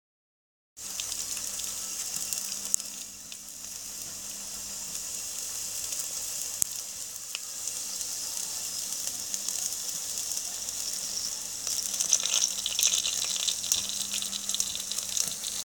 Звуки жарки, гриля
Шипение сырого мяса на сковороде